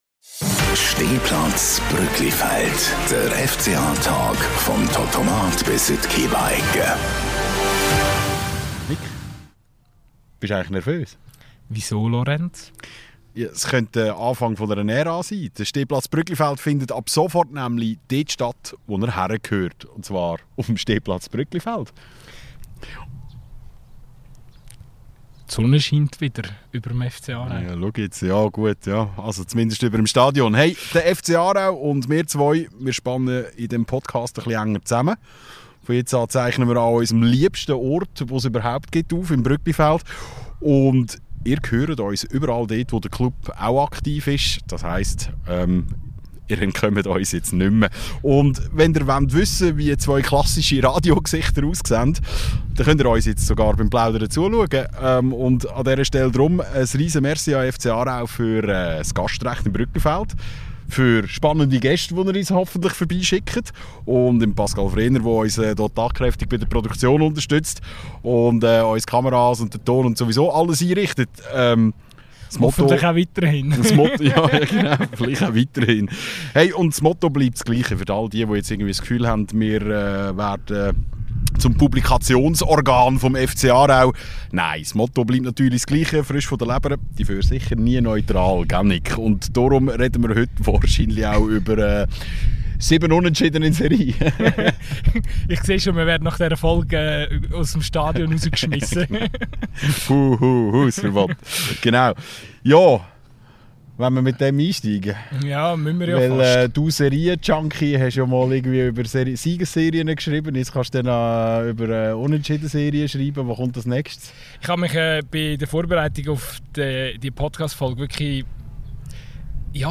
und zwar direkt auf der Stehrampe im kultigsten Stadion der Schweiz. Denn es gibt noch Big News zu verkünden: Stehplatz Brügglifeld kooperiert ab sofort mit dem FC Aarau.